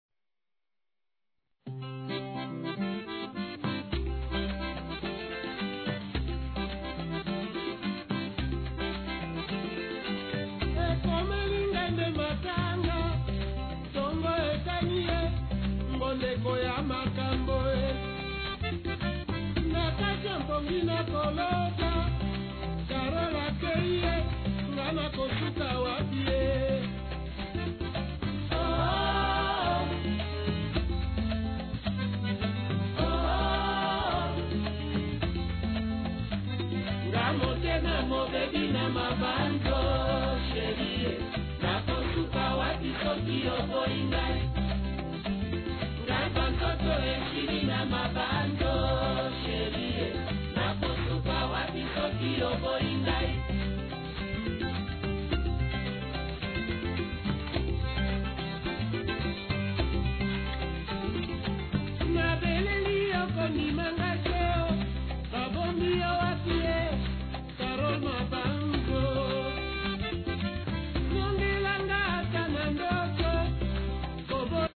music is gentle, yet filled with passion.